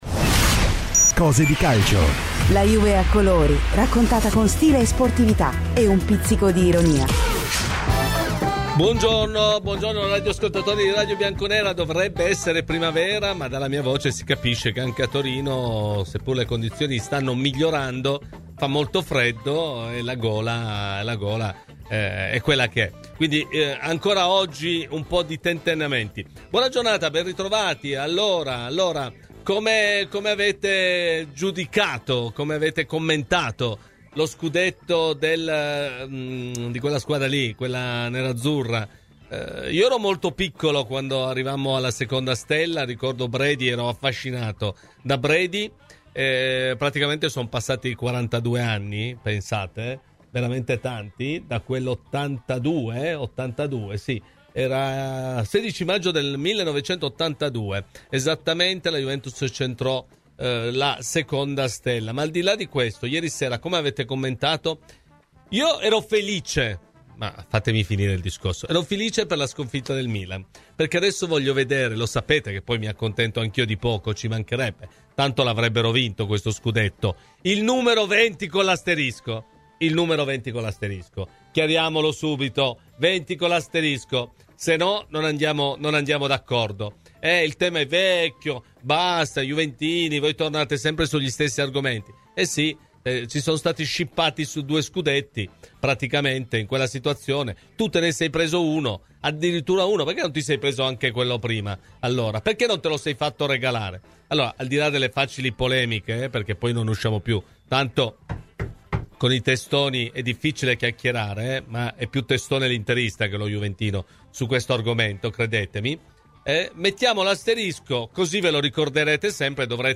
Ospite di "Cose di Calcio" su Radio Bianconera